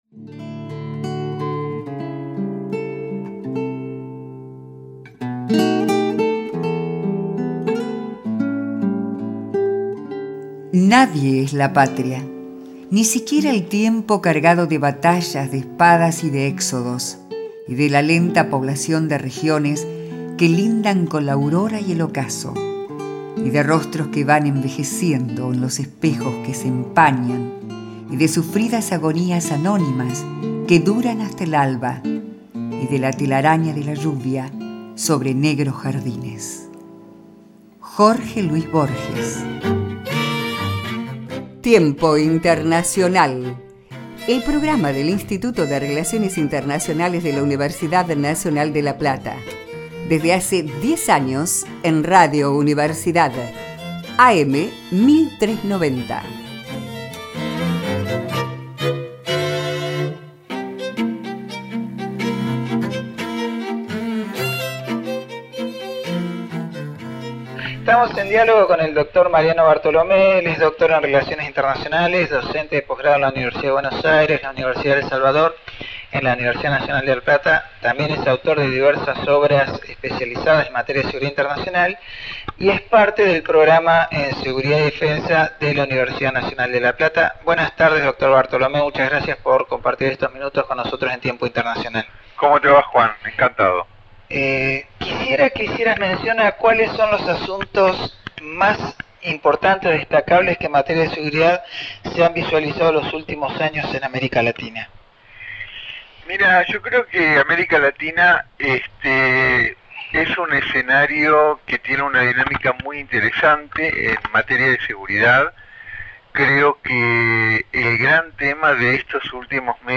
El conflicto de la Seguridad Internacional analizada por dos especialistas en el tema y docentes de la Universidad Nacional de La Plata